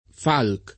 vai all'elenco alfabetico delle voci ingrandisci il carattere 100% rimpicciolisci il carattere stampa invia tramite posta elettronica codividi su Facebook Falk [ted. falk ; it. falk ; ingl. f 0 ok o f 0 o N k ; russo fa N k ; sved. falk ; ungh. fålk ] cogn.